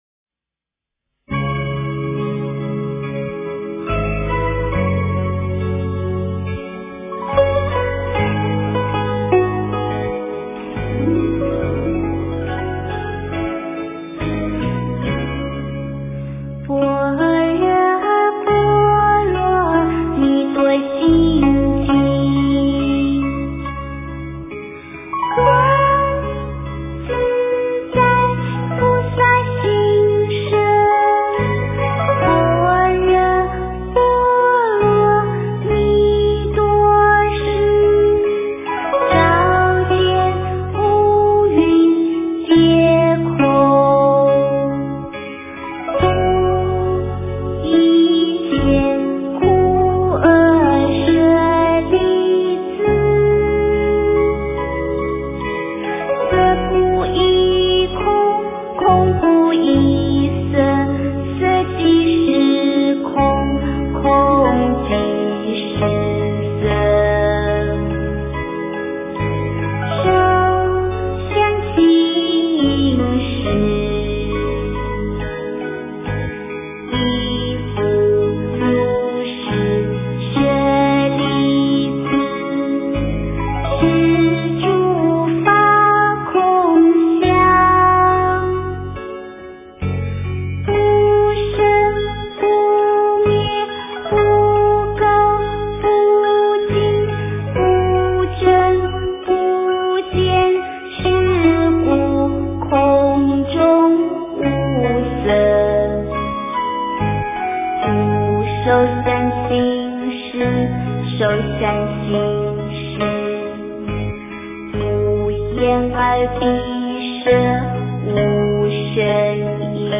诵经
佛音 诵经 佛教音乐 返回列表 上一篇： 心经 下一篇： 大悲咒-藏传 相关文章 维摩诘经-文殊师利问疾品第五 维摩诘经-文殊师利问疾品第五--未知...